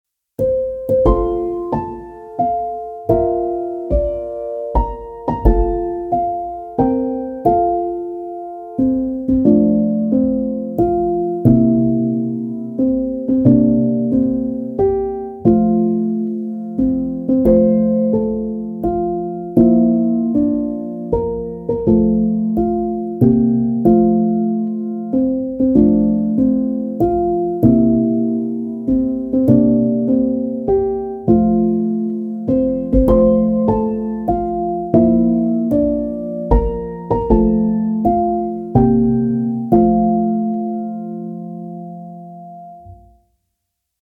happy-birthday-to-you-piano.mp3